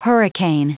Prononciation du mot hurricane en anglais (fichier audio)
Prononciation du mot : hurricane